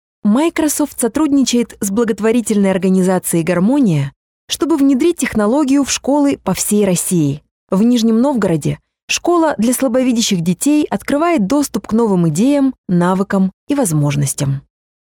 Native speaker Female 30-50 lat
Nagranie lektorskie